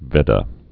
(vĕdə)